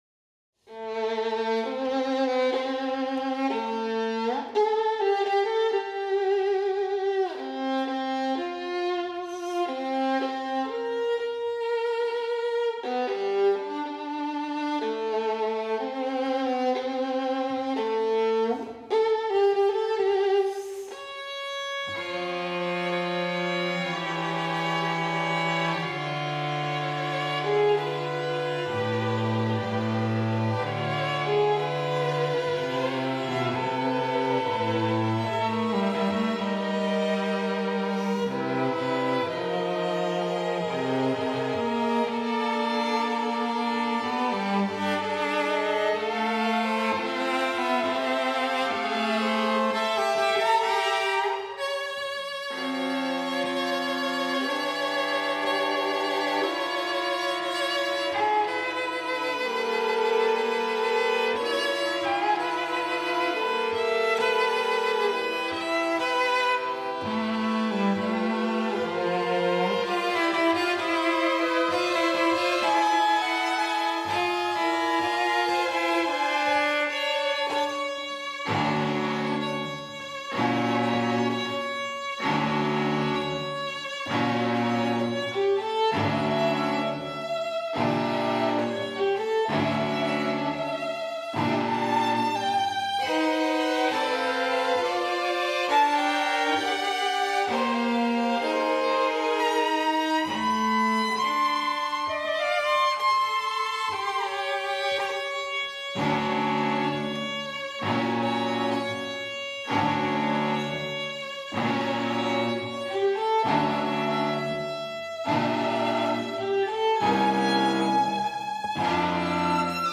Venue: St. Brendan’s Church
violins
viola
cello